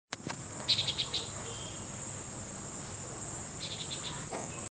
Añapero Querequeté (Chordeiles gundlachii)
Nombre en inglés: Antillean Nighthawk
Localización detallada: Plaza Independencia
Condición: Silvestre
Certeza: Vocalización Grabada